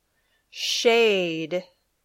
/e/ and /eI/ Sounds
shade.mp3